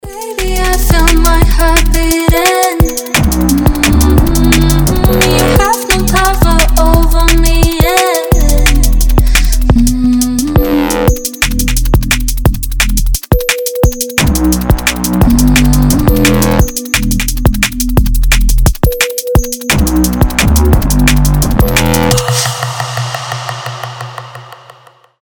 атмосферные
мелодичные
женский голос
Electronic
драм энд бейс
Красивый драм для звонка